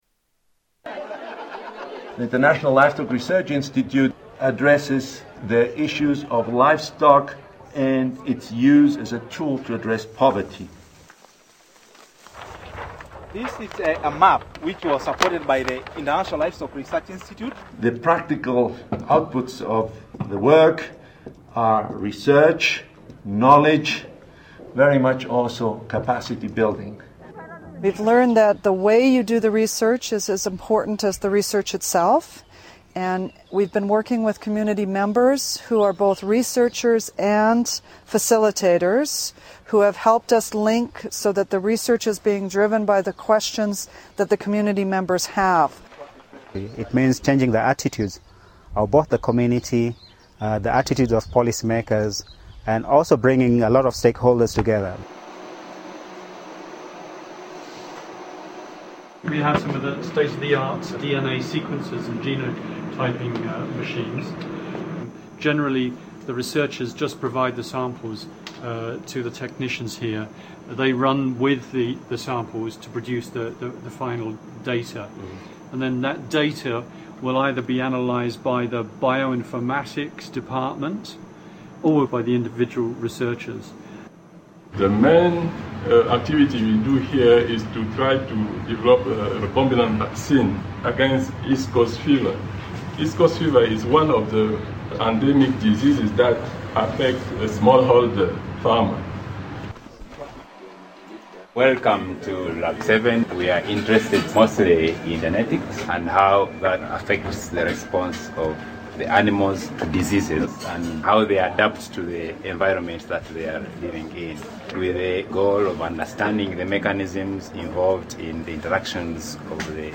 Tags: Historical Top 11-20 Censored News Stories 2009 Censored News Media News Report